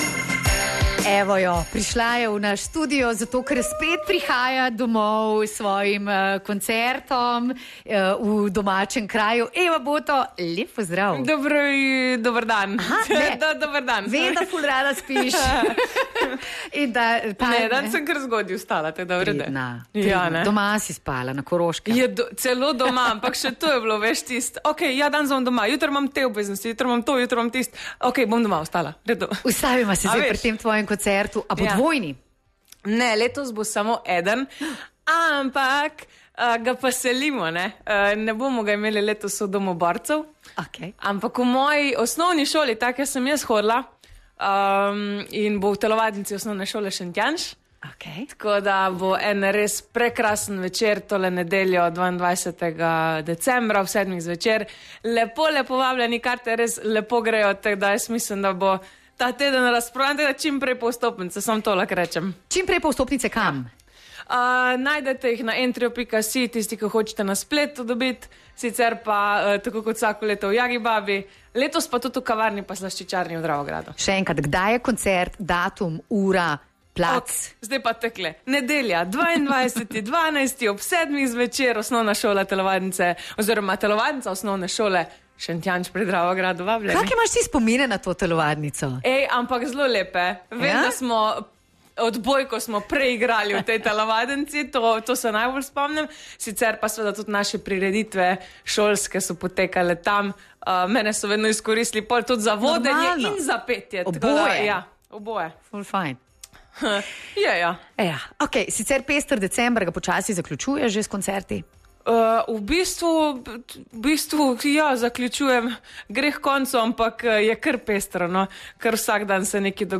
Najbolj nasmejana koroška pevka Eva Boto, kot vsako leto, pripravlja božični koncert v svojem domačem Šentjanžu pri Dravogradu. Kjer najbolj gori - tradicionalen koncert v Šentjanžu bo to nedeljo, 22.12.2024 ob 19.uri. Eva Boto se je ustavila v našem studiu in kar sama povabila na koncert.